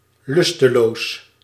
Ääntäminen
France: IPA: [a.pa.tik]